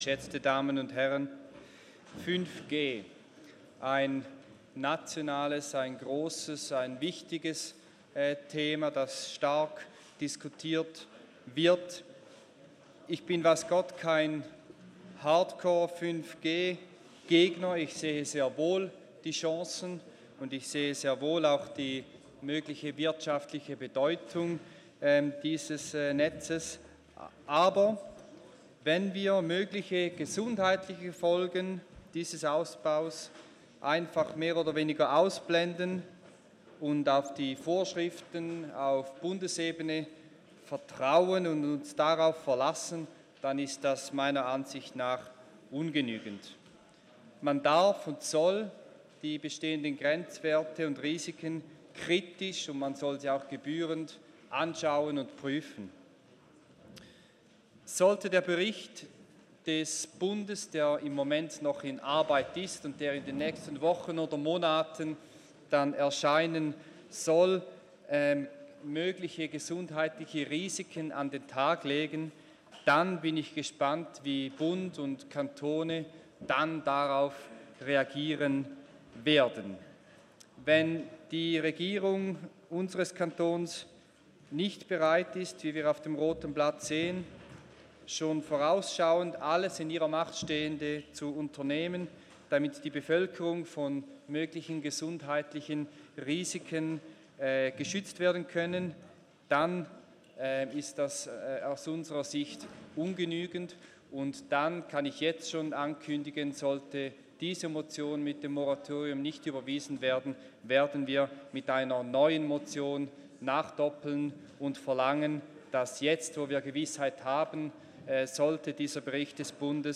12.6.2019Wortmeldung
Session des Kantonsrates vom 11. bis 13. Juni 2019